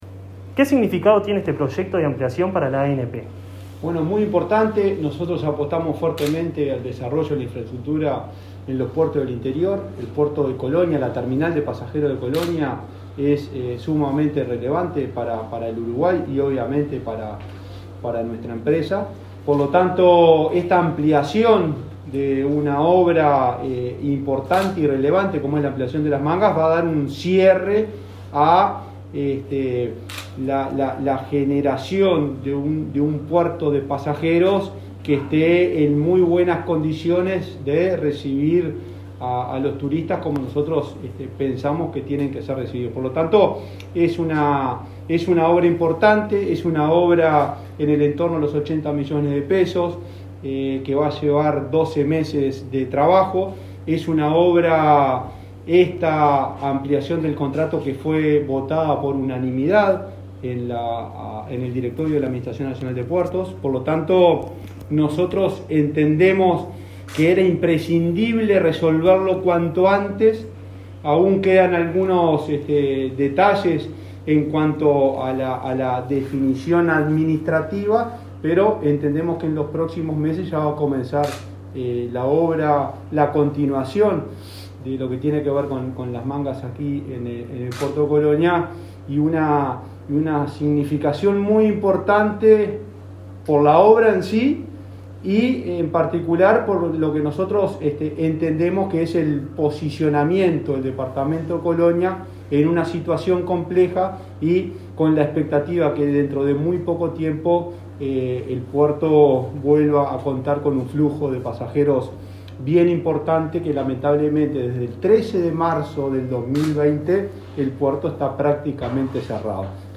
Palabras del presidente de la Administración Nacional de Puertos, Juan Curbelo